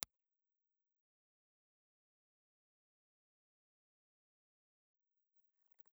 Impulse Response file of the Toshiba B ribbon microphone without bass cut
Toshiba_B_0_IR.wav